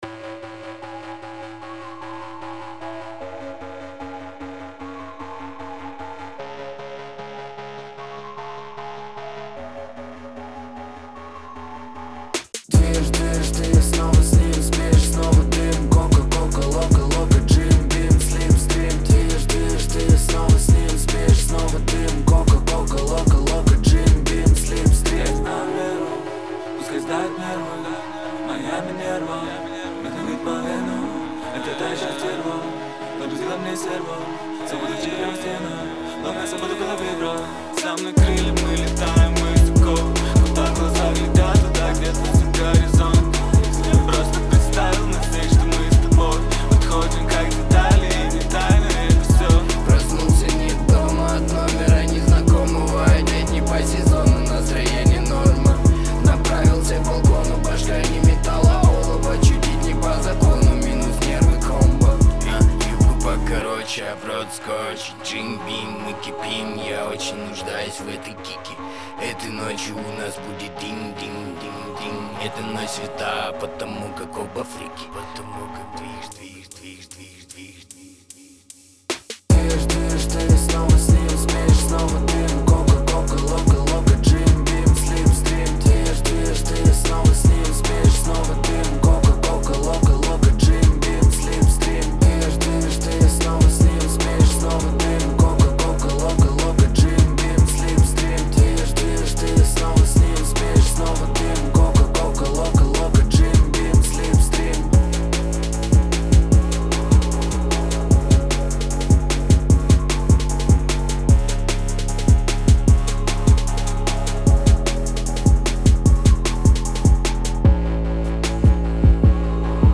это яркая и энергичная композиция в жанре хип-хоп